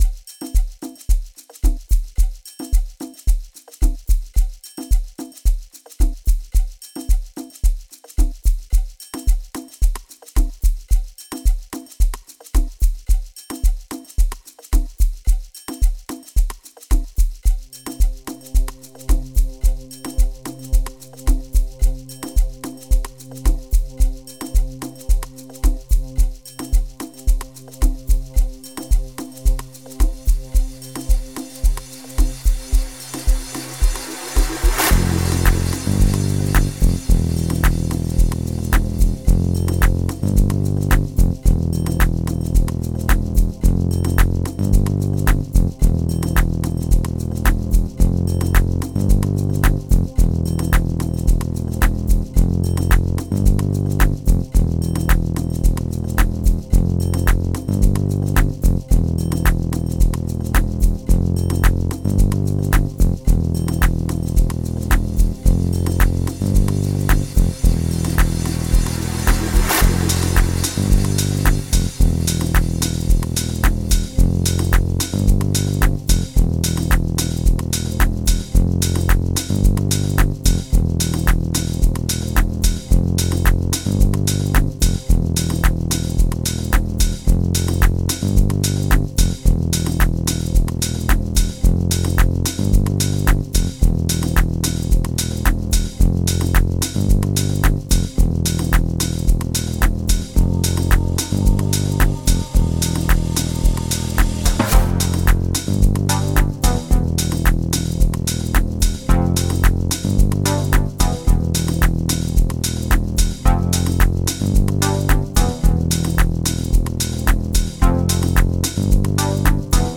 Private School Amapiano song